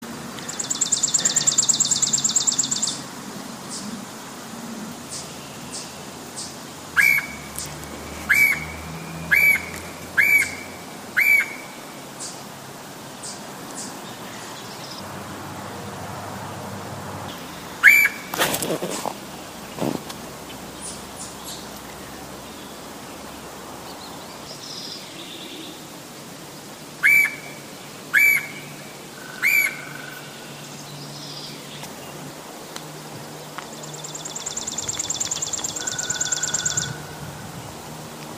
• 5/4/15 -- Sterling
sound recording of the Red-headed Woodpecker, in mp3 format. The call starts at the 7 second mark.